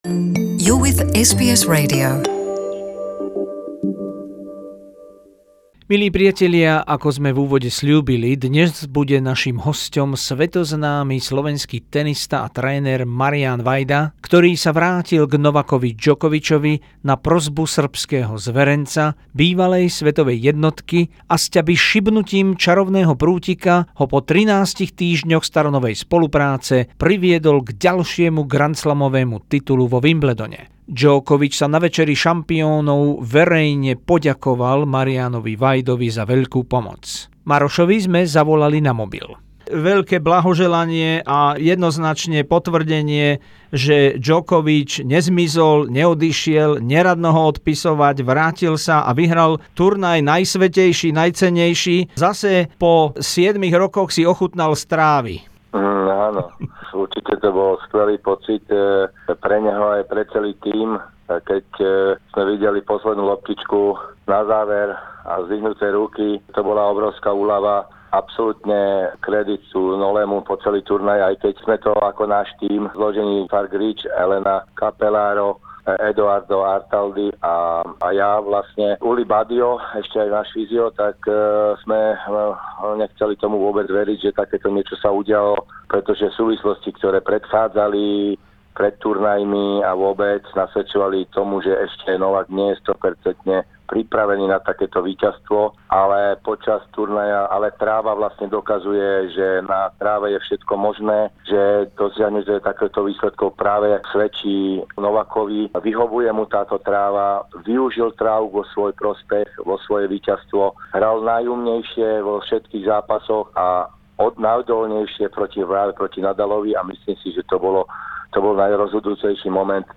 SBS exclusive interview with the Slovak tennis coach Marian Vajda, who works with Novak Djokovic since 2006 and returned to him just 13 weeks ago and the reunion resulted in another, 13th Grand Slam title in Wimbledon.